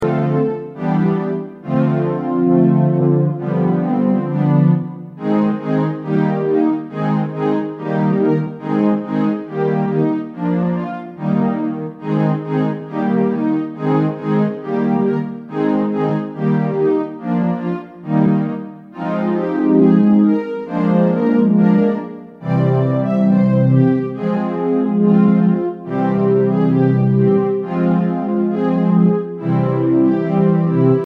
Listen to the instrumental backup track.